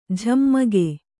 ♪ jhammage